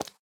sounds / mob / frog / step4.ogg